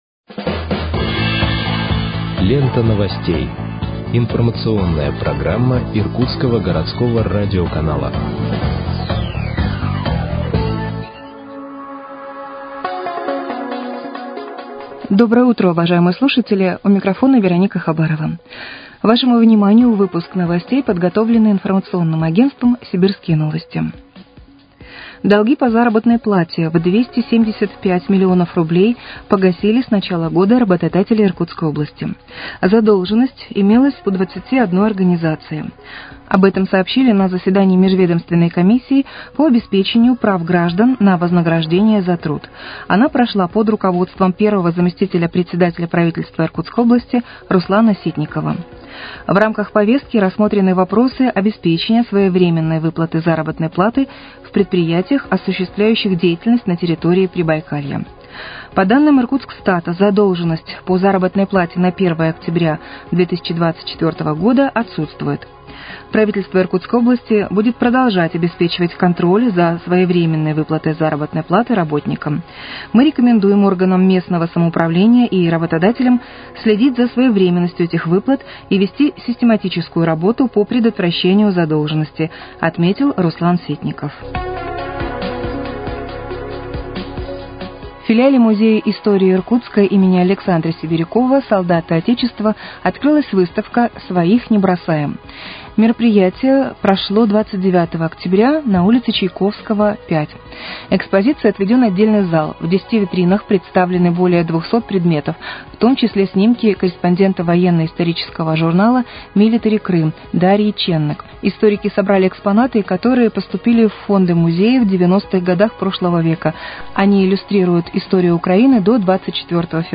Выпуск новостей в подкастах газеты «Иркутск» от 31.10.2024 № 1